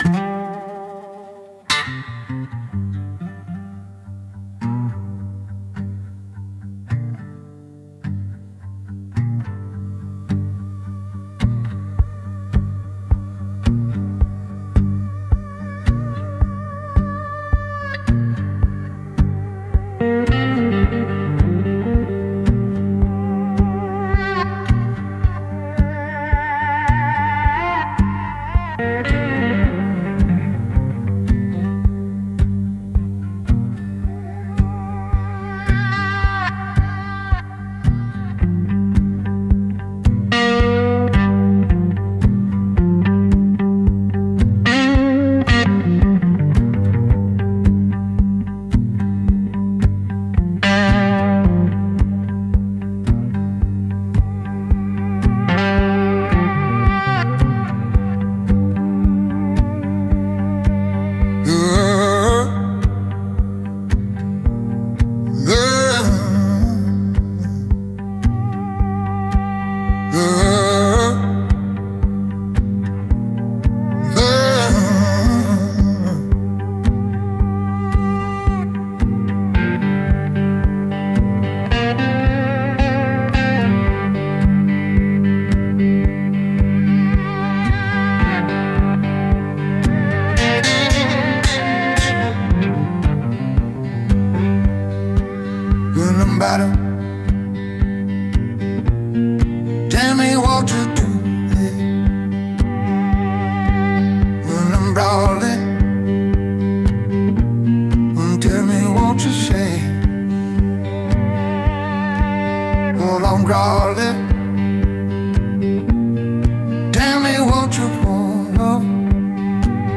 Instrumental - Muddy Water Memory